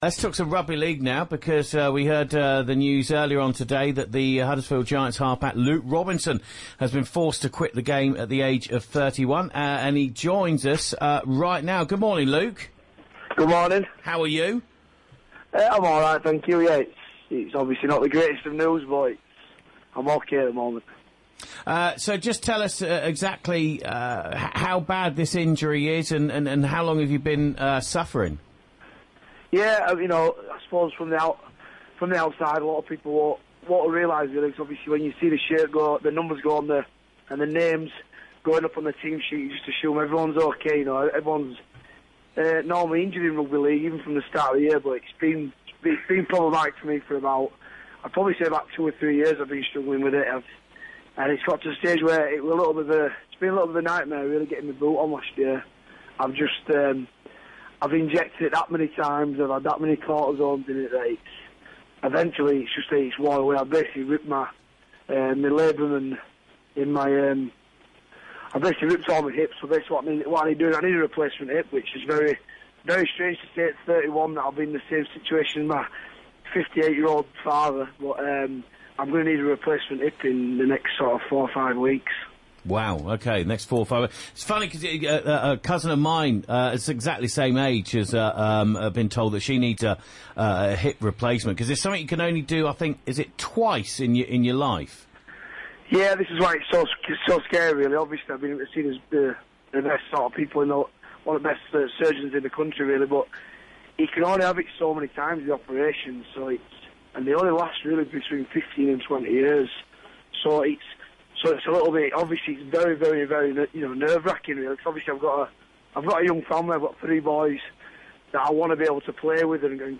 Huddersfield Giants half back Luke Robinson talks to Radio Yorkshire about his retirement